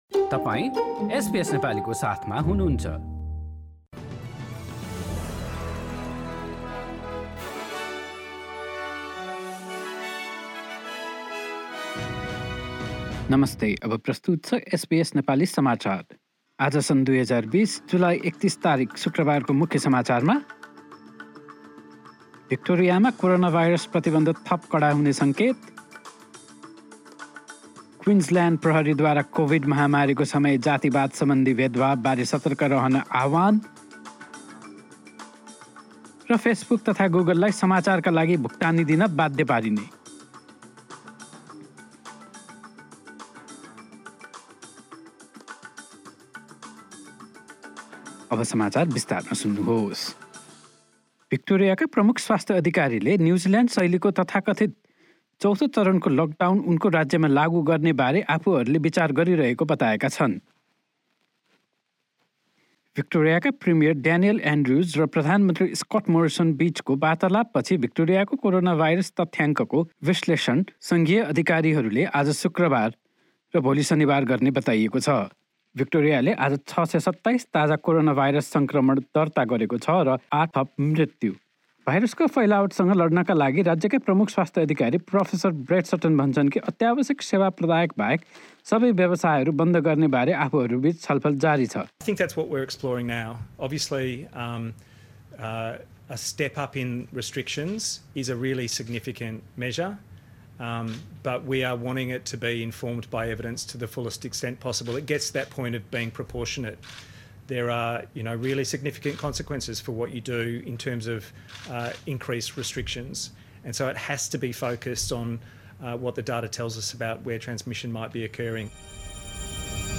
एसबीएस नेपाली अस्ट्रेलिया समाचार: शुक्रवार ३१ जुलाई २०२०